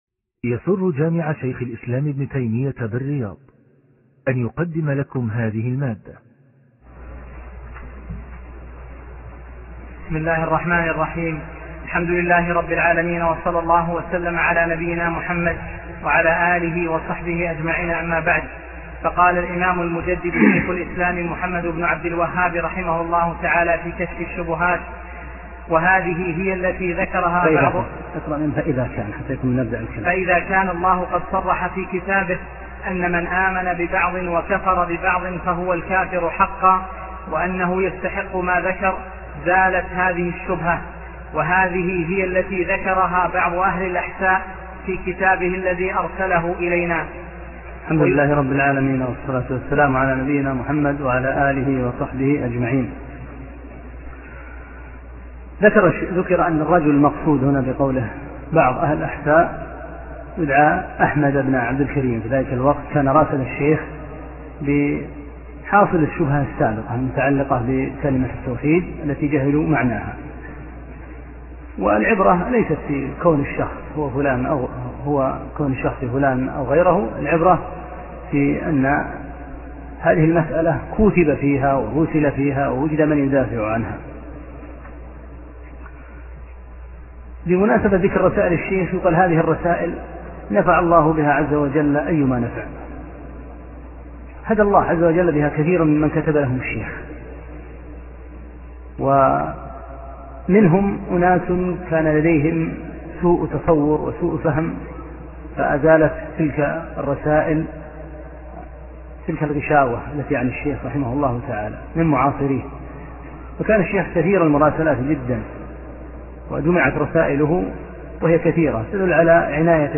6- الدرس السادس